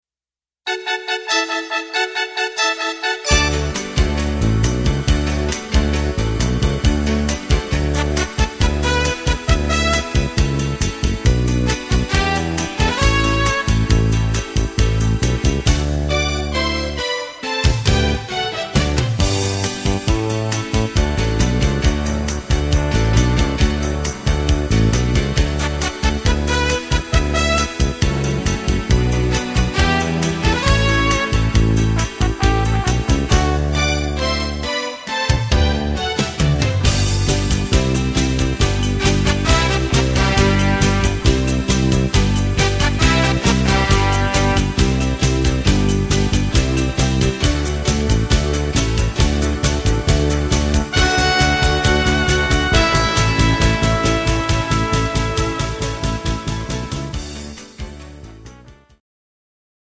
Trompete